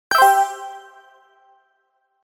messagealert1.mp3